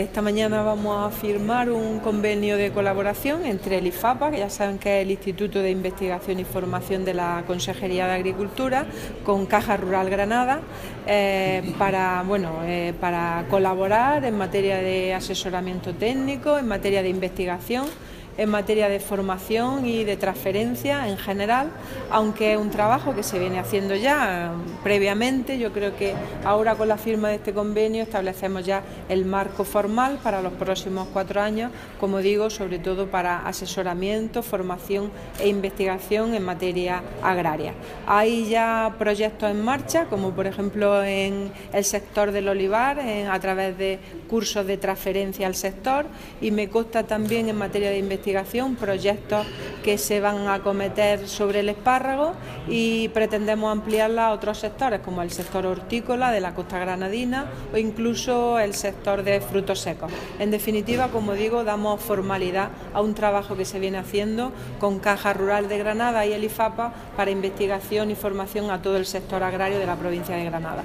Declaraciones de Carmen Ortiz sobre la firma del convenio entre Ifapa y Caja Rural Granada